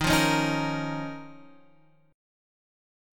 D#7sus2 chord